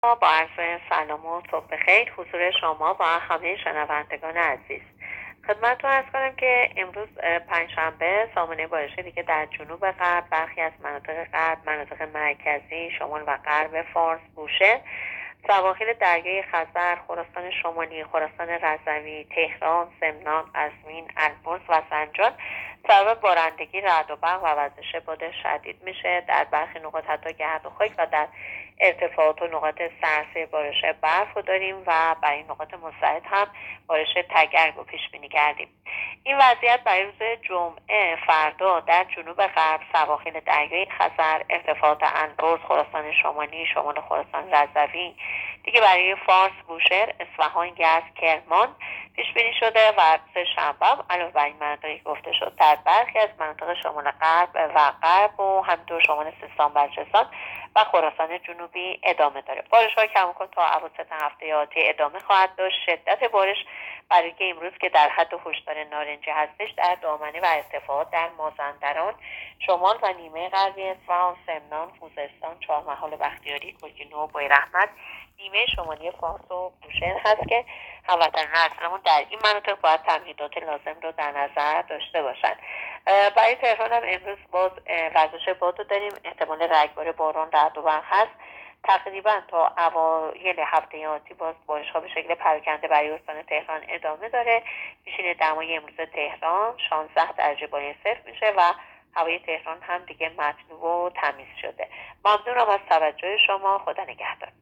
گزارش رادیو اینترنتی پایگاه‌ خبری از آخرین وضعیت آب‌وهوای ۲۰ آذر؛